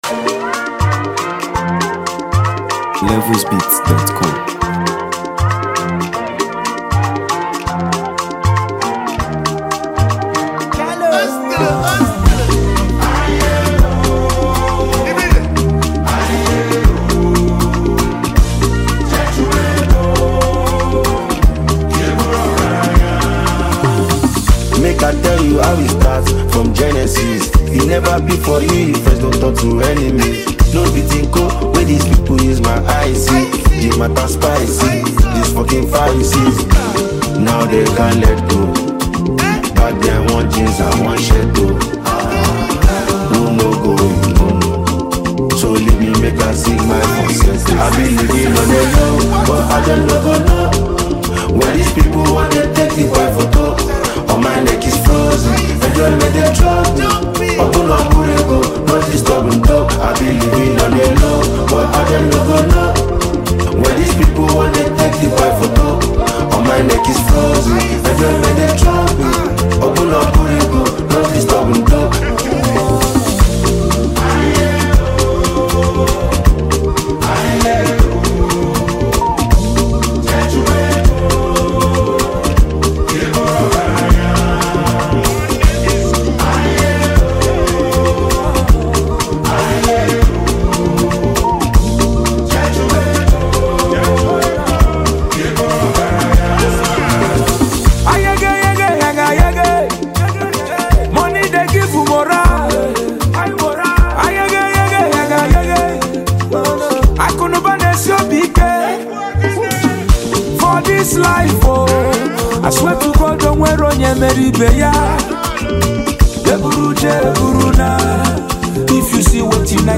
soulful delivery